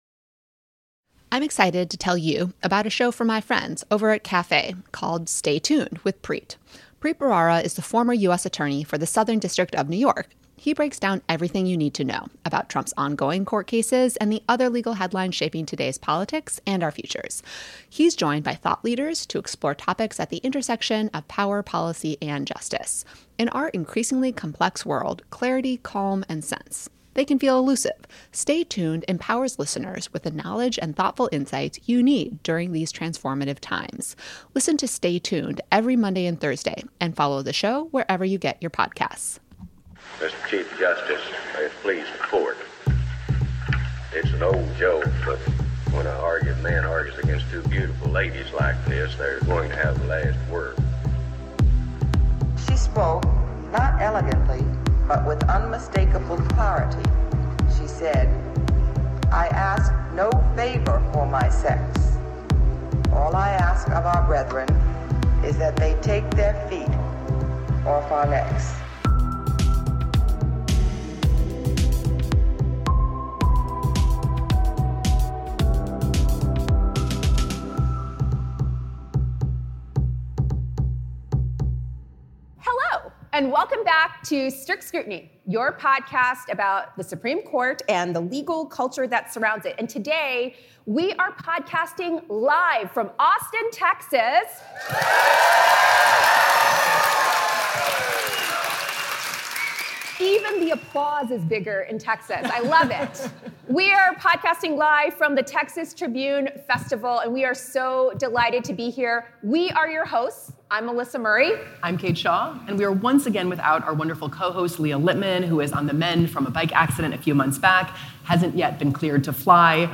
Reform, Repression, & Reproductive Rights (Live from Texas!)